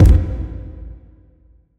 CC - Crystal Kick.wav